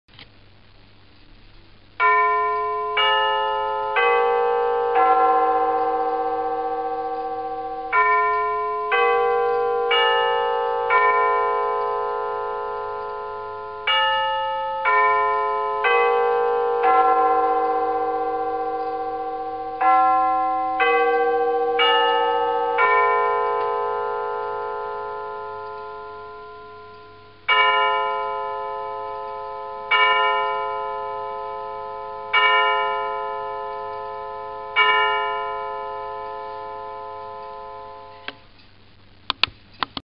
Čas řízený signálem - absolutně přesný - automatická změna zimního / letního času NÍŽE SI POSLECHNĚTE SI ZVUK BITÍ.
MELODII WESTMINSTER S ODBÍJENÍM SI MŮŽETE POSLECHNOUT: ZDE